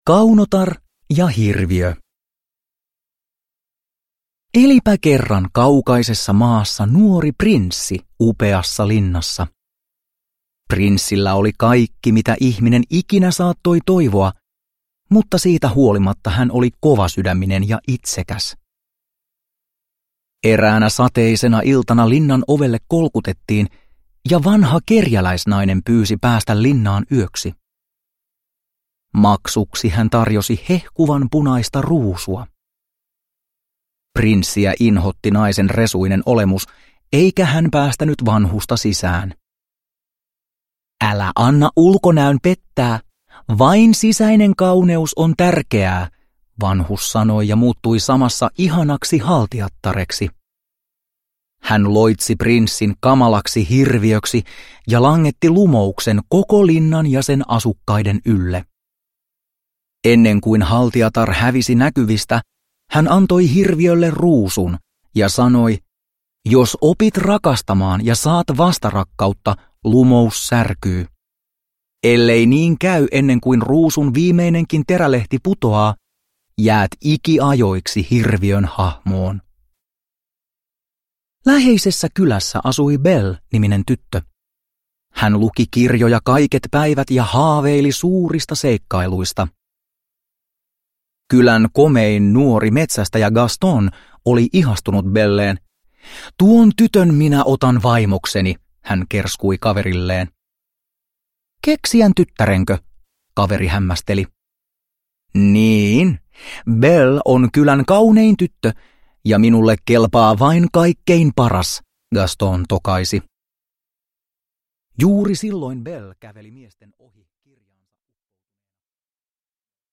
Kaunotar ja Hirviö – Ljudbok – Laddas ner